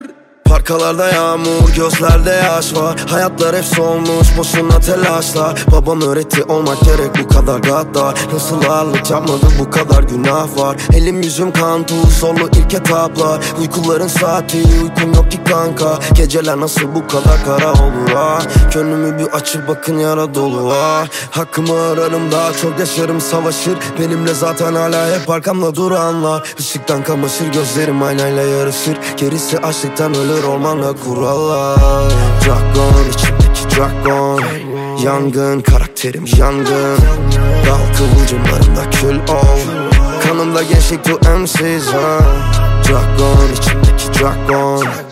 Kategori Rap